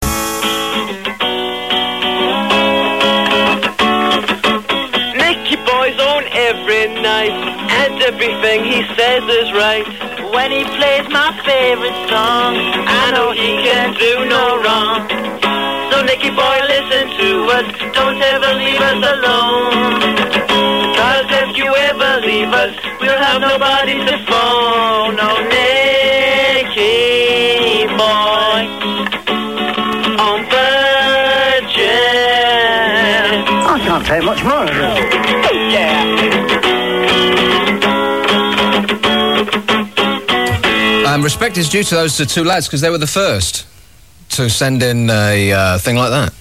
These were made by fans of his show.